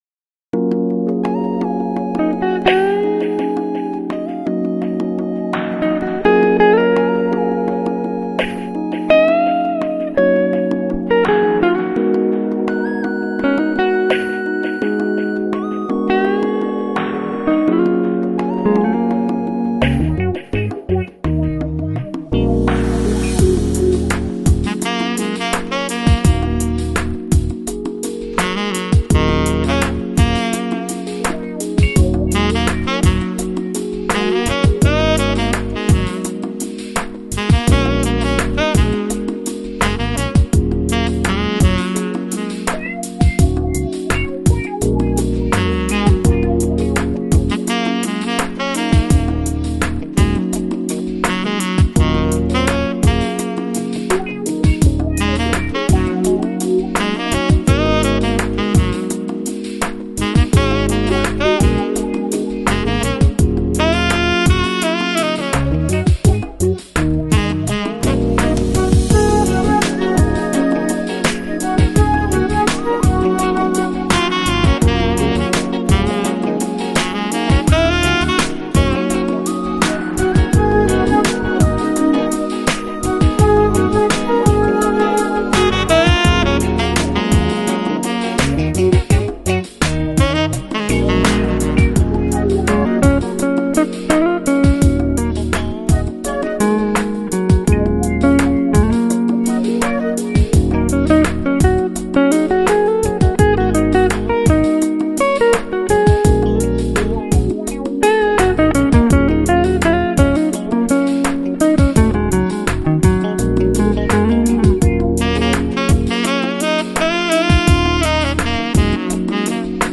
Жанр: Smooth Jazz, Chill Out, Contemporary Instrumental